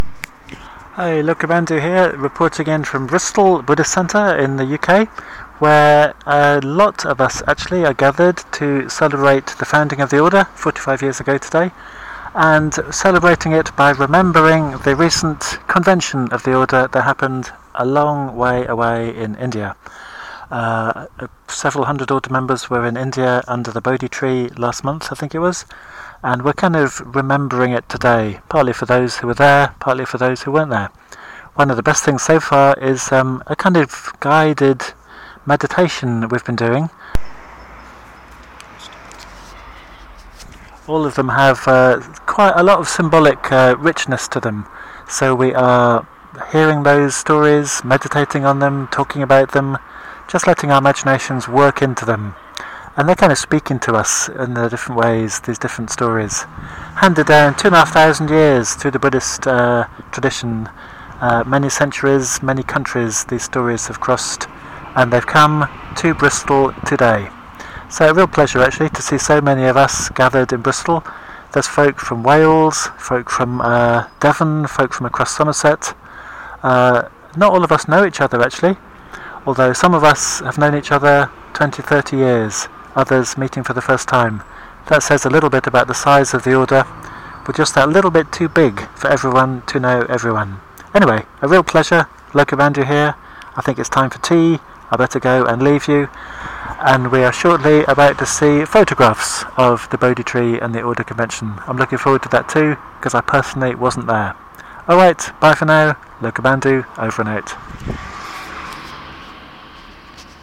Buddhist celebrations from Bristol in the UK to mark the festival of the Buddha's awakening to the nature of existence and his seeing of how to move past suffering....